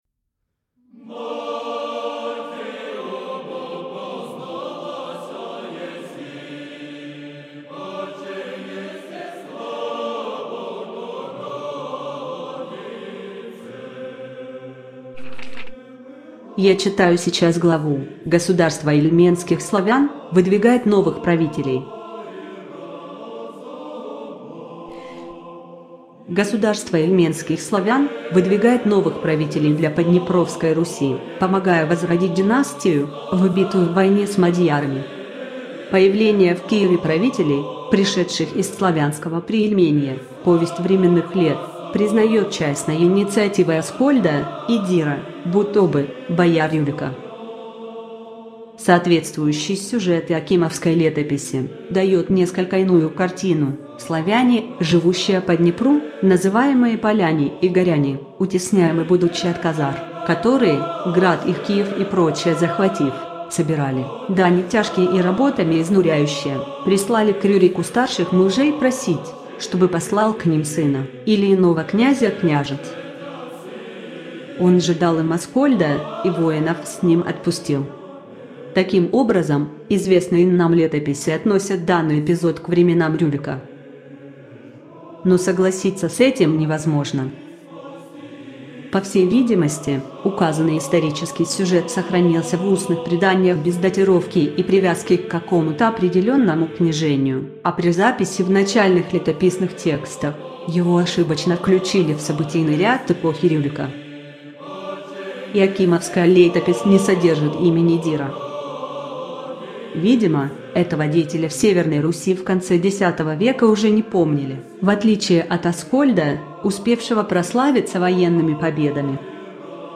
Автор Татищев Василий Никитич из аудиокниги "Иоакимовская летопись".